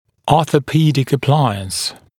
[ˌɔːθə’piːdɪk ə’plaɪəns][ˌо:сэ’пи:дик э’плайэнс]ортопедический аппарат